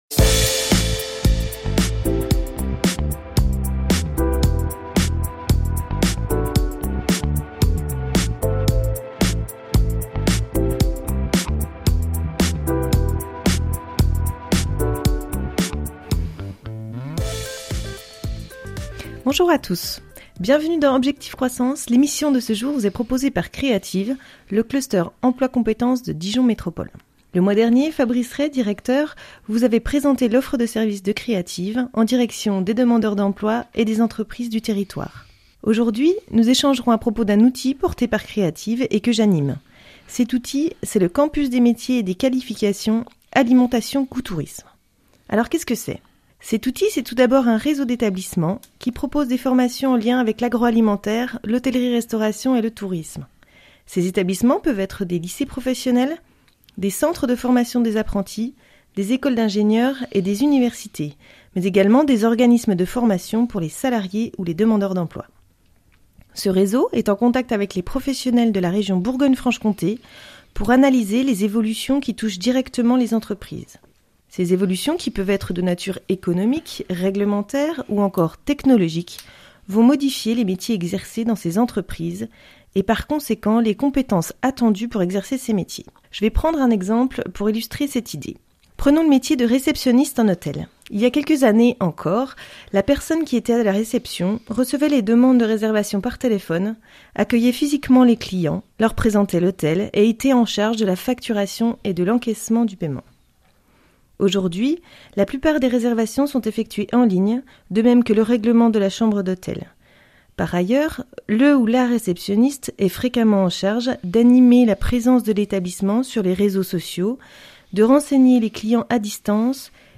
Dans l'émission hebdomadaire de RCF, Objectif croissance,